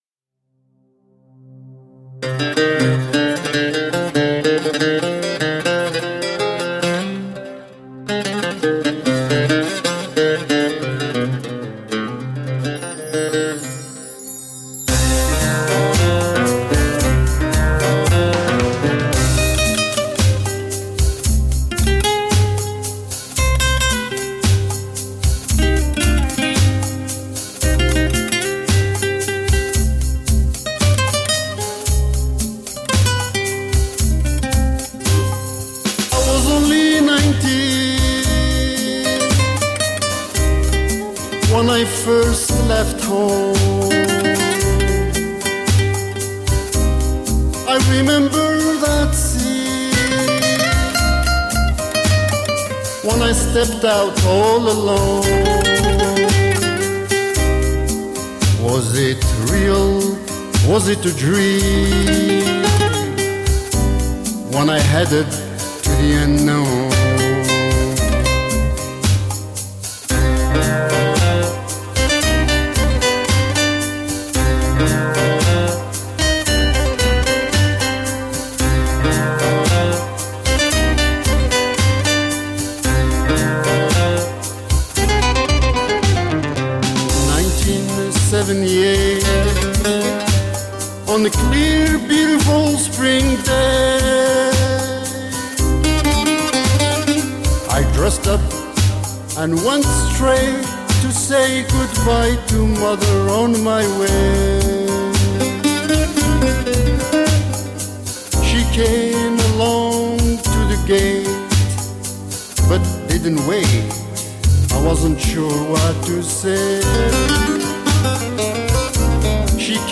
Music of the mountains of kabylia.
Tagged as: World, Folk, Arabic influenced, World Influenced